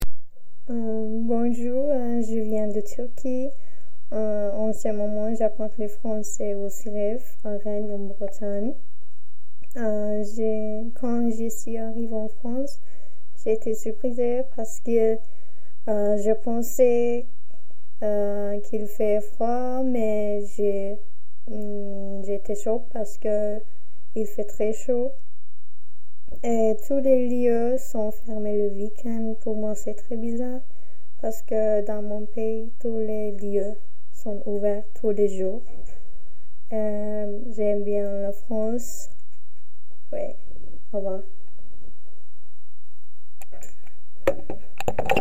Cabine de témoignages
Témoignage du 24 novembre 2025 à 17h04